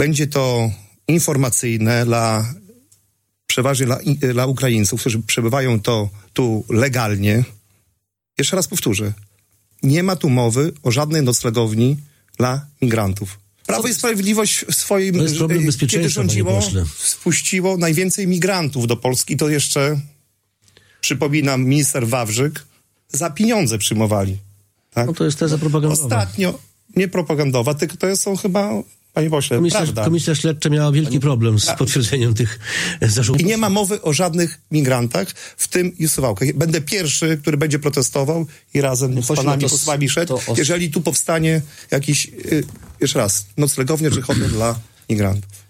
– Budujmy, bo znowu zamieszamy i drogi nie będzie – mówi Jacek Niedźwiedzki, poseł Koalicji Obywatelskiej o budowie drogi Białystok-Augustów.